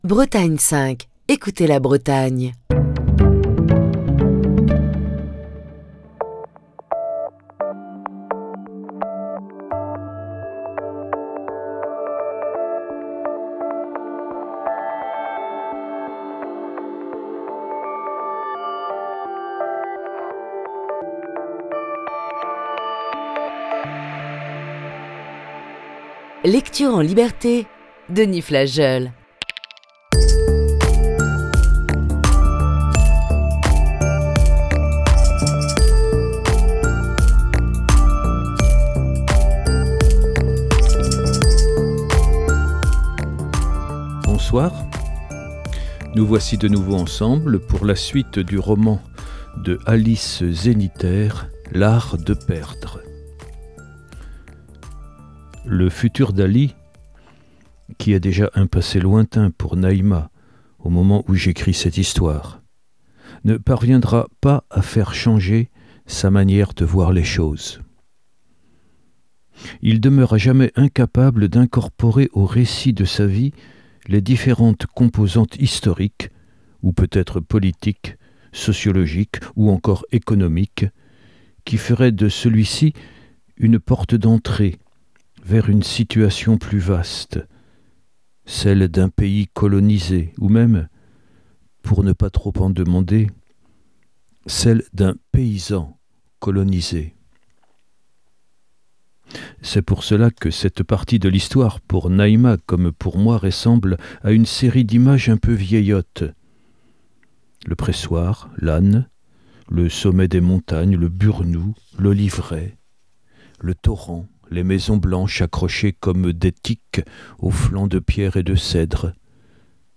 la lecture du roman d'Alice Zeniter, "L'Art de perdre"